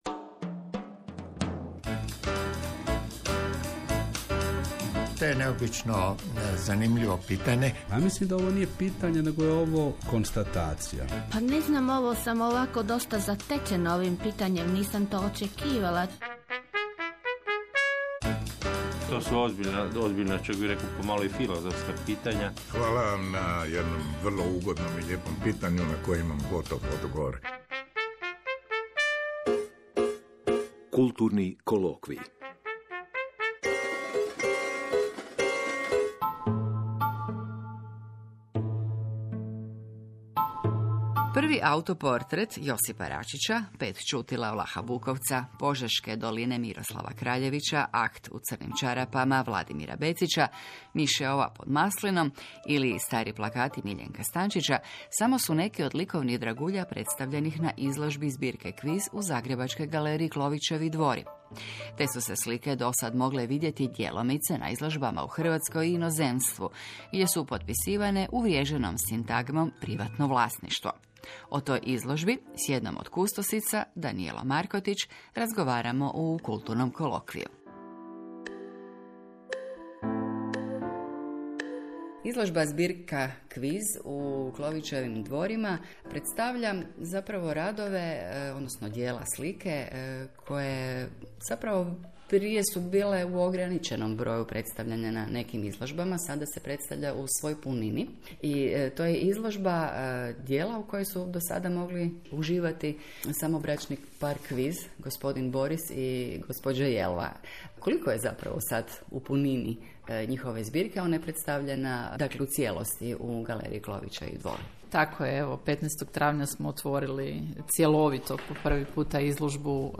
Emisija intervjua "jedan na jedan" s nekim od aktivnih sudionika naše kulturne scene, a koji uz pitanja voditelja u studiju odgovara i na već snimljena pitanja kolega s kulturne scene, ljudi iz privatnog života, kritičara, prijatelja.